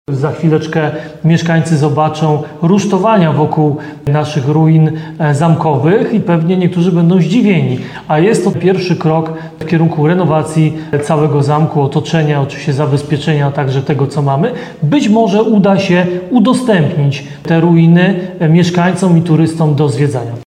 Być może uda się udostępnić te ruiny mieszkańcom i turystom do zwiedzania – podkreślił prezydent Ludomir Handzel.